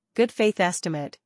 gud - fayth - es.tɪ.meɪt